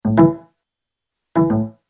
Звук флешки
Отличного качества, без посторонних шумов.